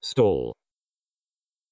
stall_voice.wav